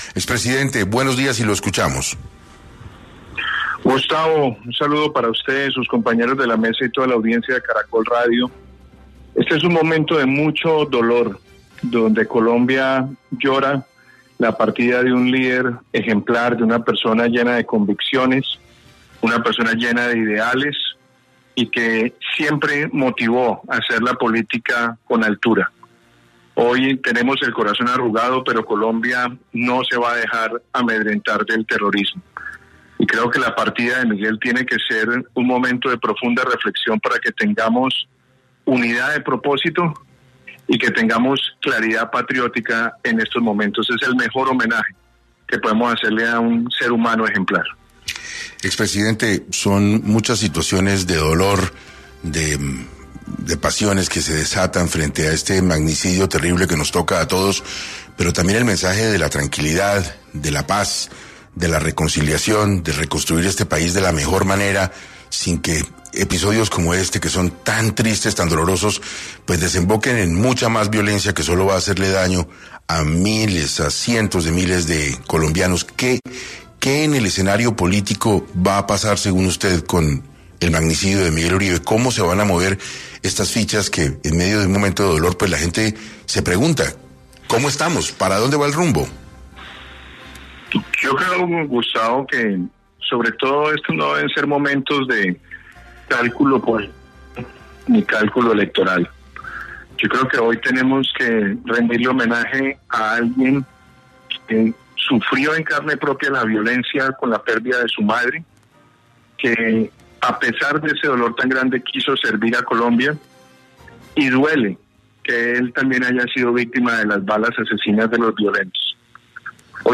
El expresidente Iván Duque se pronunció en Caracol Radio y comentó que lamenta el fallecimiento de Miguel Uribe Turbay.